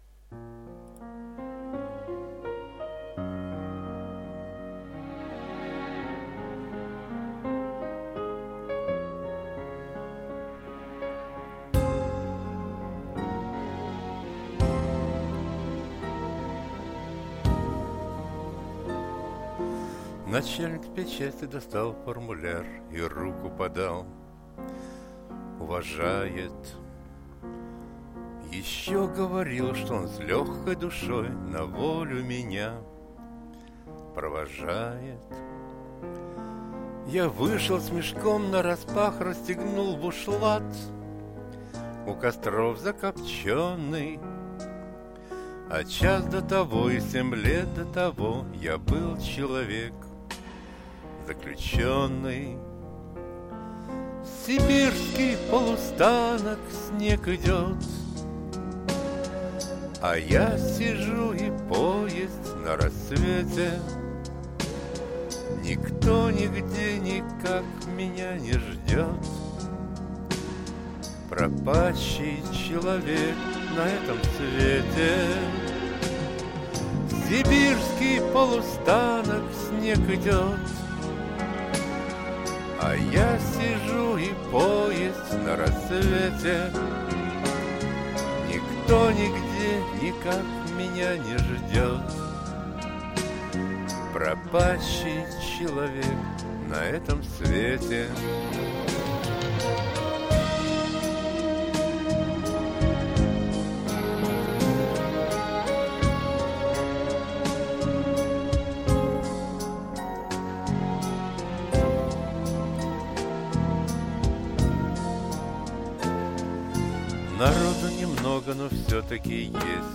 Голос не для шансона.